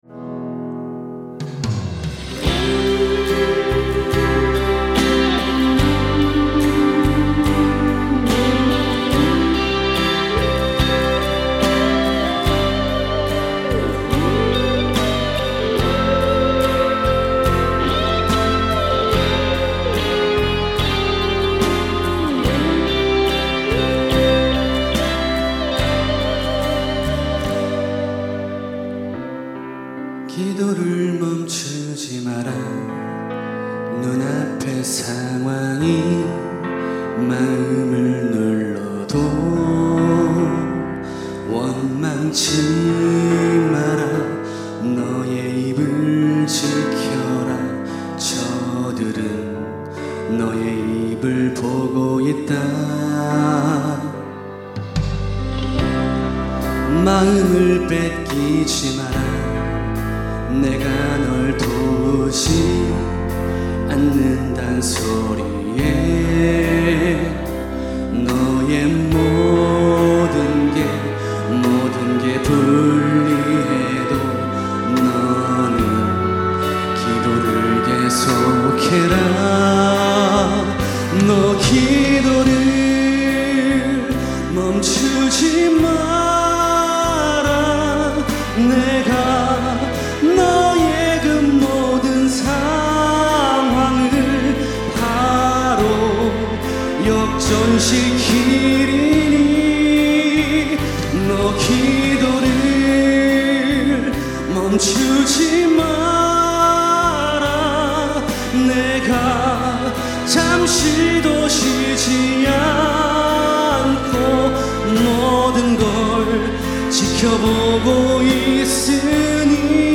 특송과 특주 - 이제 역전되리라